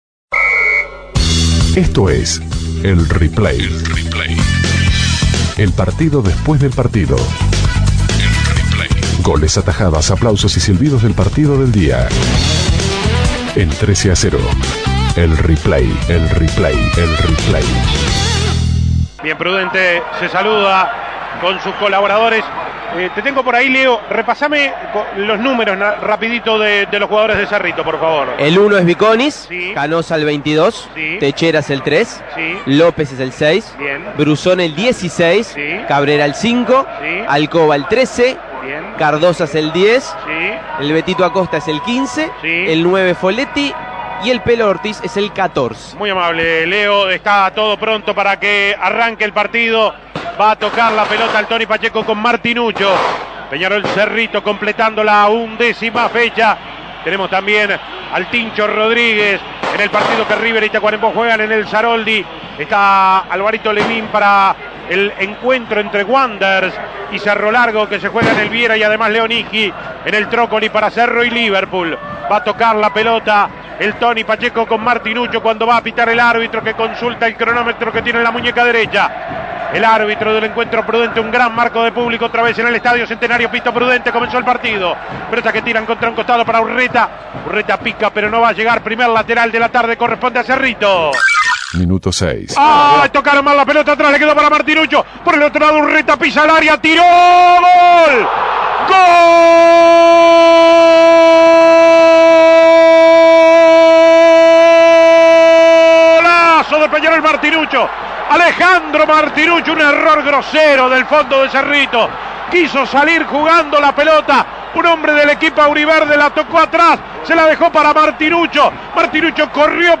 Escuche los goles y las principales incidencias del partido entre Peñarol y Cerrito que le posibilitó a los aurinegros despegarse en la tabla anual.
Goles y comentarios